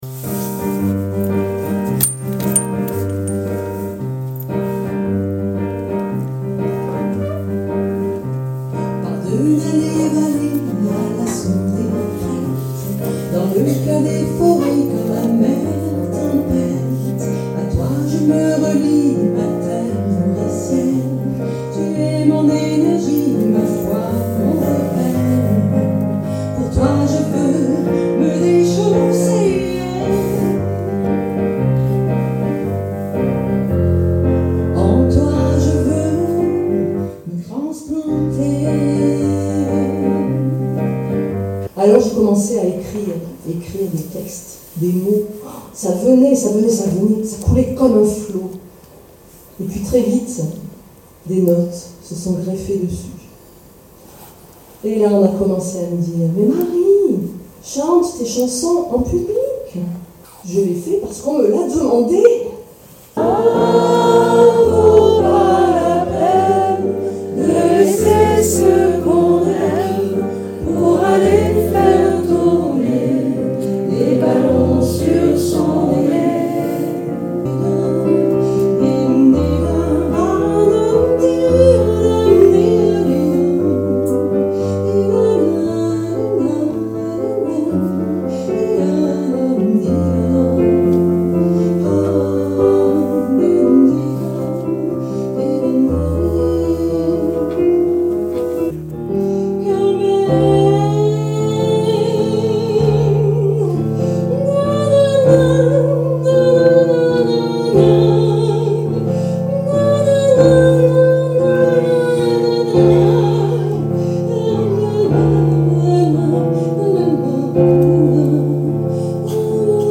le refrain repris par le public
Extraits du concert